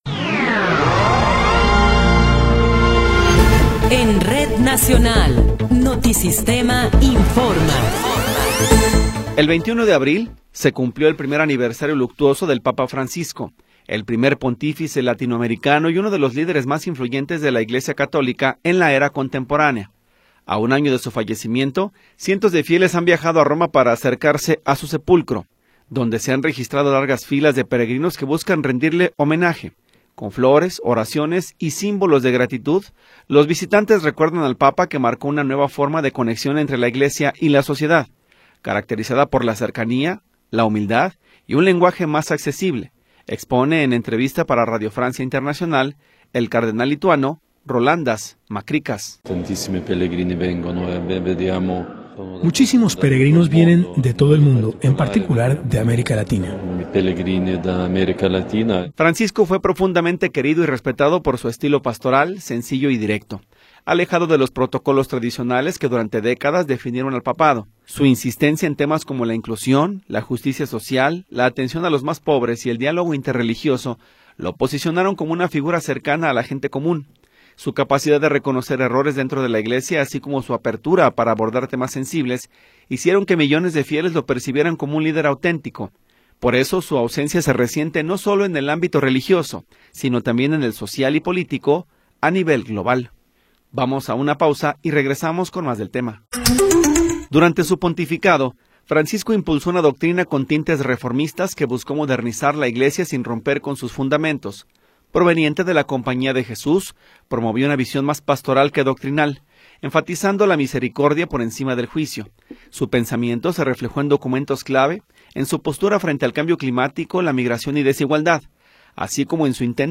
Noticiero 13 hrs. – 26 de Abril de 2026
Resumen informativo Notisistema, la mejor y más completa información cada hora en la hora.